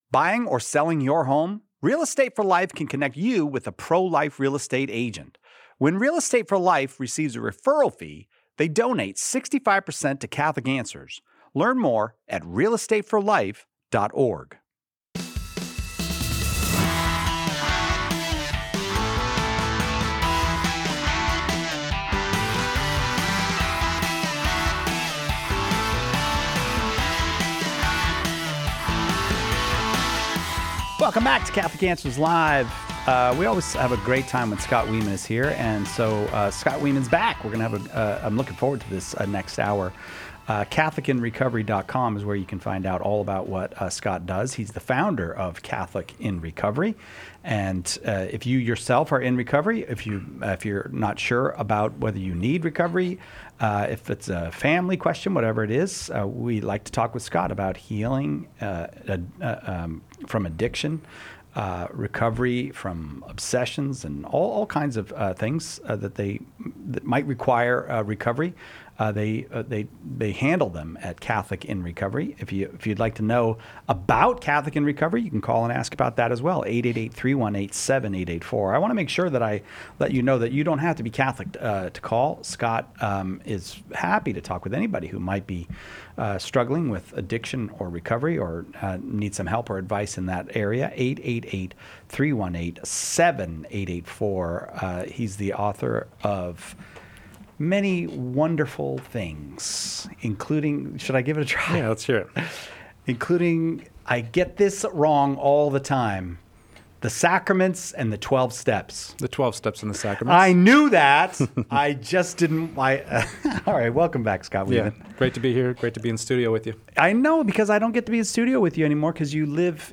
Listeners ask how to find Catholic support groups, whether addiction to spending money can destroy relationships, and if those with mental health diagnoses like schizoaffective disorder can benefit from Catholic recovery programs. We also discuss the role of the sacraments in healing, how to balance recovery with public speaking, and how to reach a college-age son struggling with marijuana use.